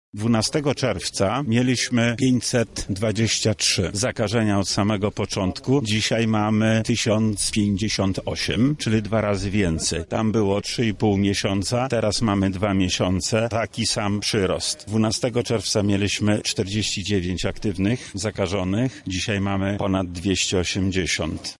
— mówi Wojewoda Lubelski, Lech Sprawka.